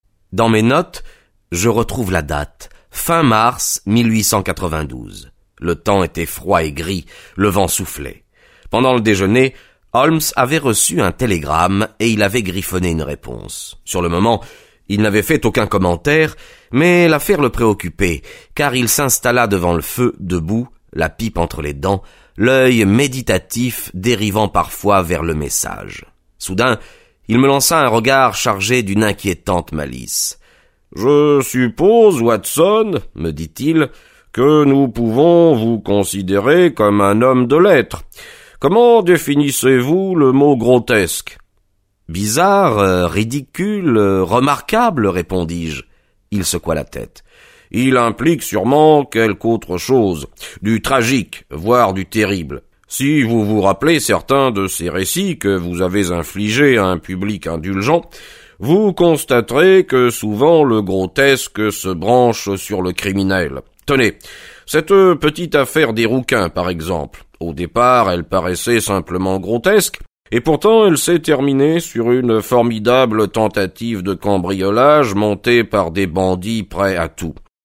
Diffusion distribution ebook et livre audio - Catalogue livres numériques
Lire un extrait - Sherlock Holmes - Wisteria Lodge de Arthur Conan Doyle